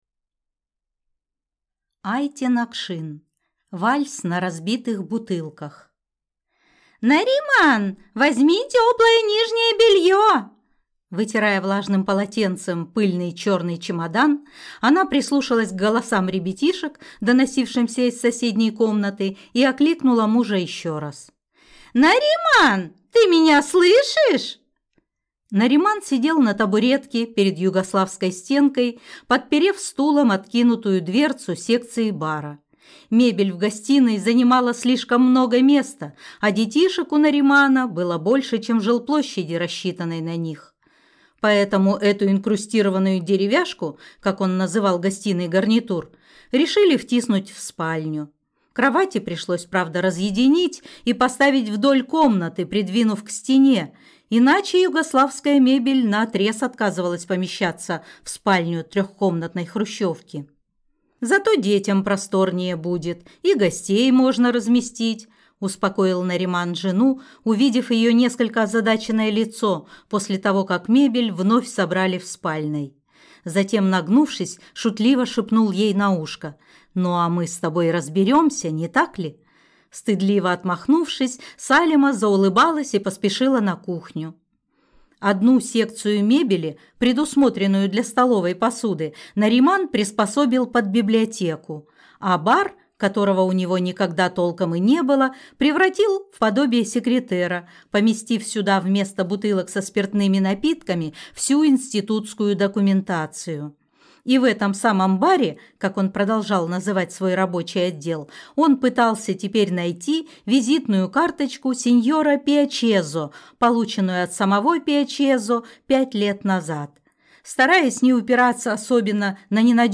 Аудиокнига Вальс на разбитых бутылках | Библиотека аудиокниг